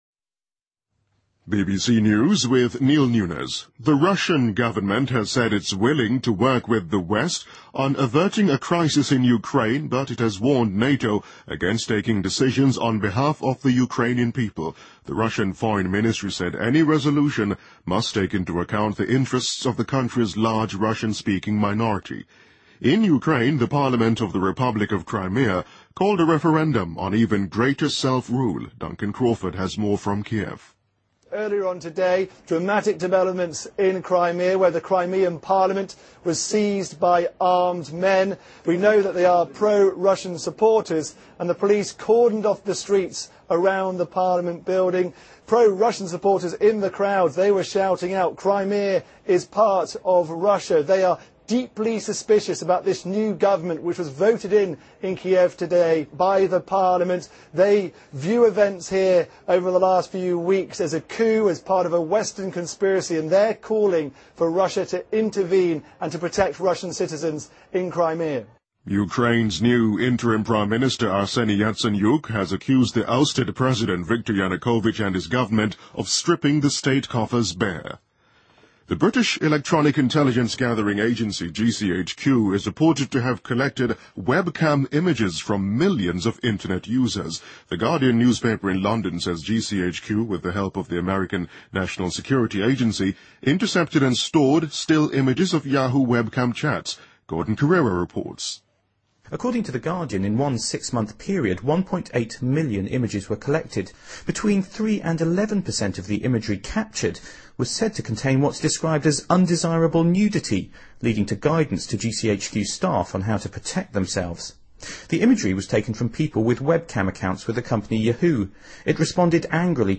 BBC news,2014-02-28